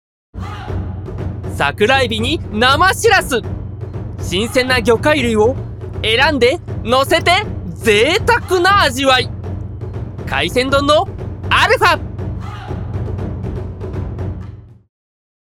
VOICE SAMPLE
元気CM スポーツCM 番宣ナレーション
【得意ボイス】元気系 / パワフル系 / チャキチャキ系 / おっとり系